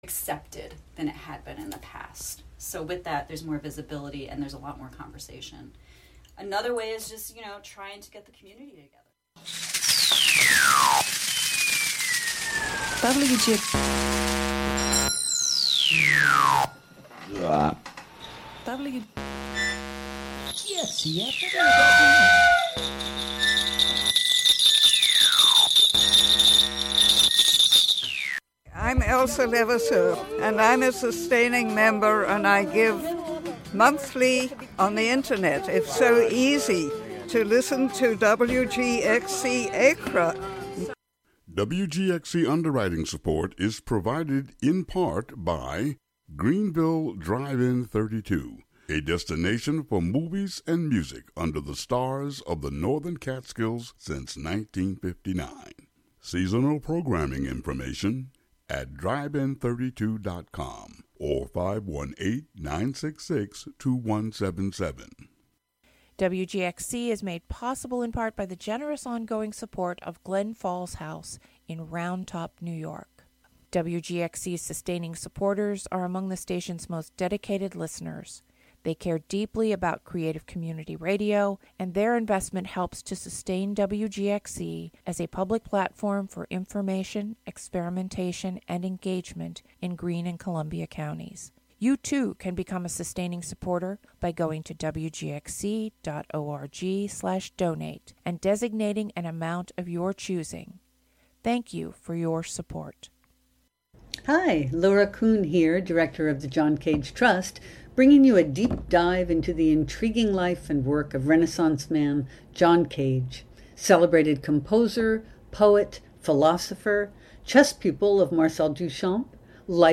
music mix show